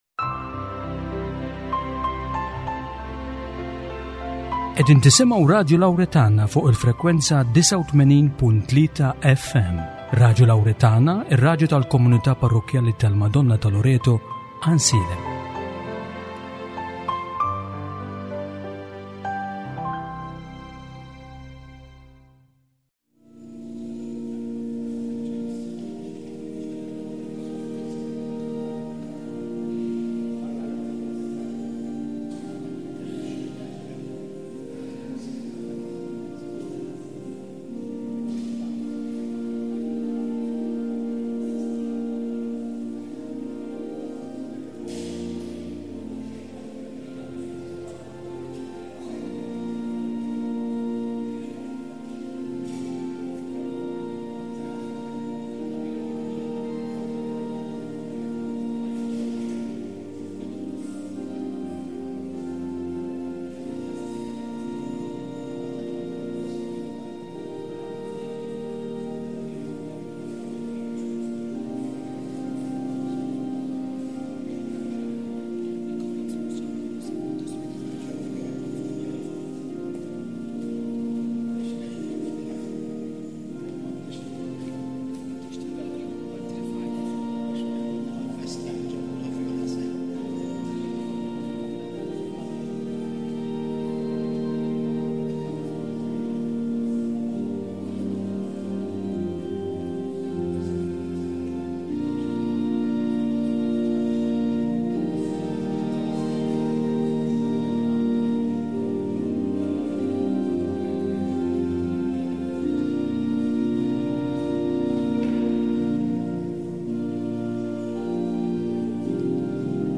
Il-Quddiesa mill-Knisja Arċipretali